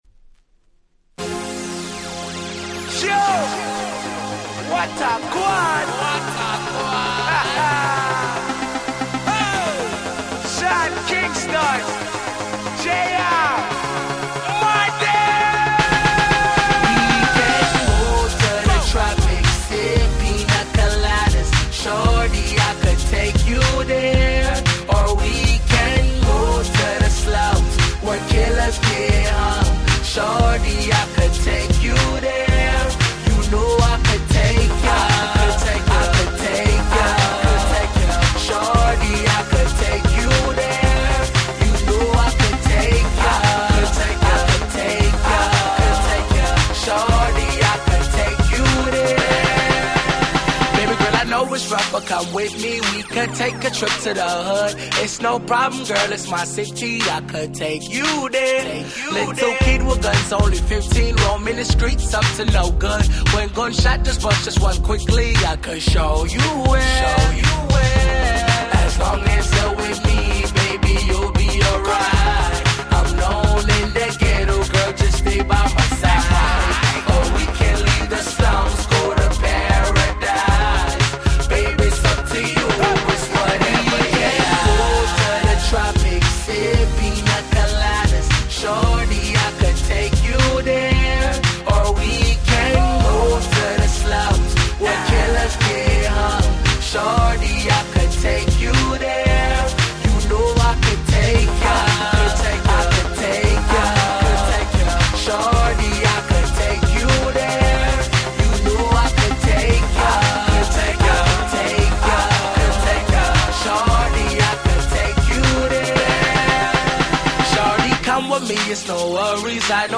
07' Smash Hit R&B !!
疾走感満点のフロアチューンで凄く格好良いです！
キャッチー系